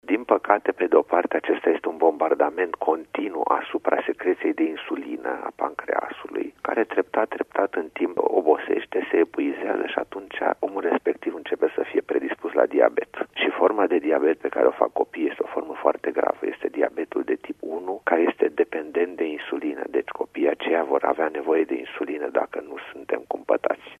stiri-25-dec-doctor-dulciuri.mp3